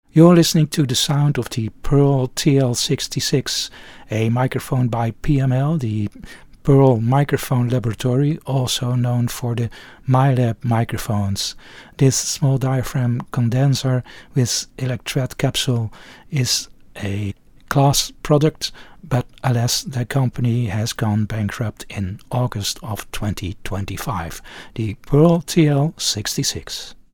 Among the somewhat more modest types is the modern classic of this month: the Pearl TL 66 small diaphragm cardioid pencil condenser, designed for studio use as well as musicians.
The capsule, an electret, came from another company, Pearl used it to create an interesting microphone with a clear and neutral sound and very low noise.
The sound was not fat, but accurate, and to the liking of some, not all.
Pearl TL66 sound UK.mp3